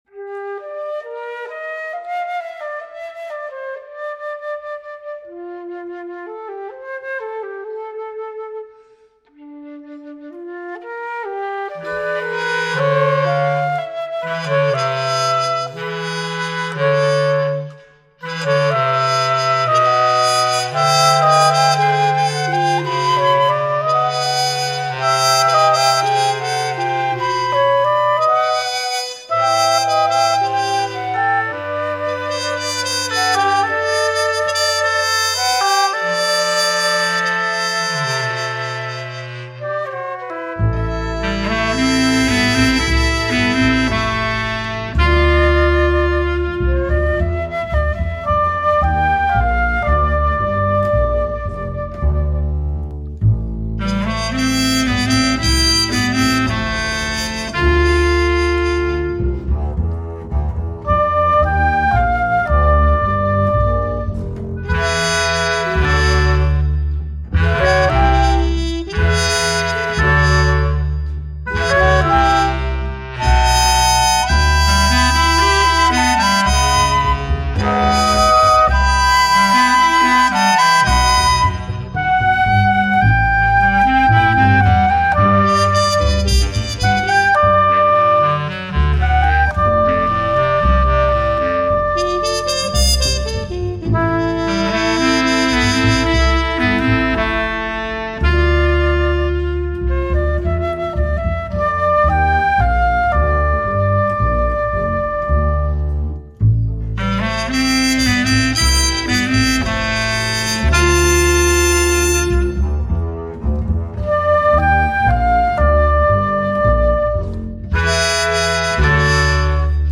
musiche invisibili per 6 esecutori e 11 strumenti